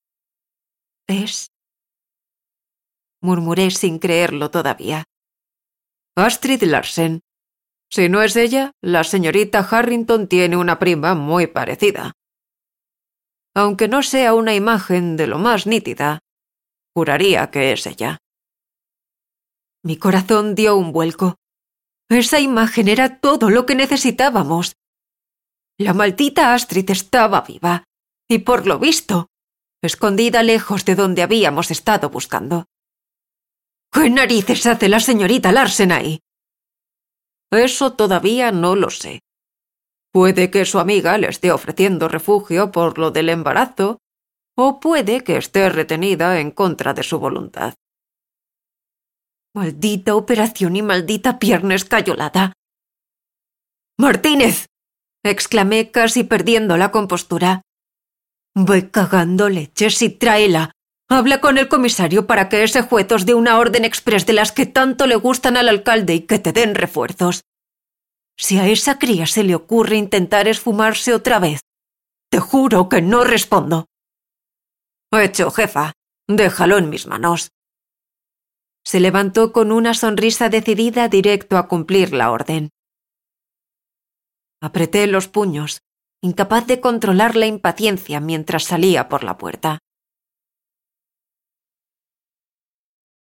Mi voz se adapta al ritmo y estilo de cada historia, creando una narrativa cautivadora.
Fragmentos de algunas narraciones de libros: